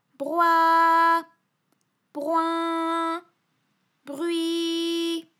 ALYS-DB-001-FRA - First, previously private, UTAU French vocal library of ALYS
broi_broin_brui.wav